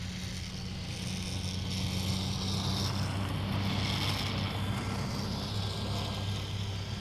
Zero Emission Subjective Noise Event Audio File (WAV)